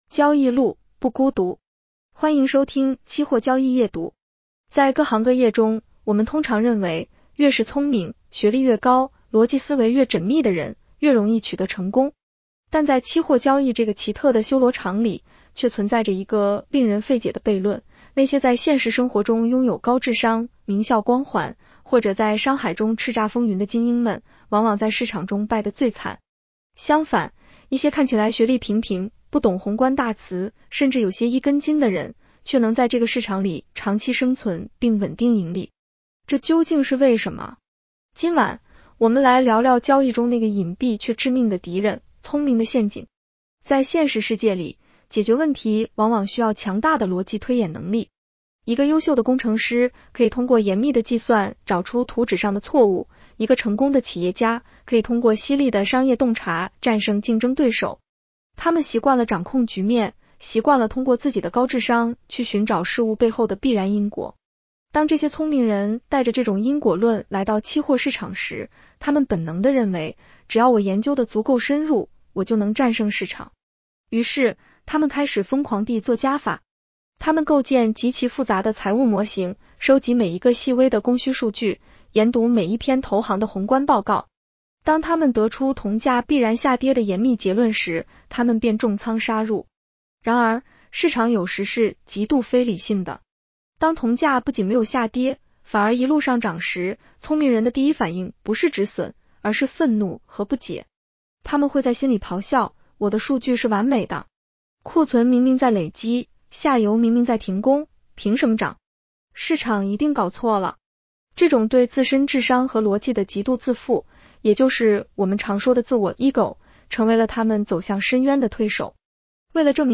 女声普通话版 下载mp3 交易路，不孤独。
（AI生成） 风险提示及免责条款：市场有风险，投资需谨慎。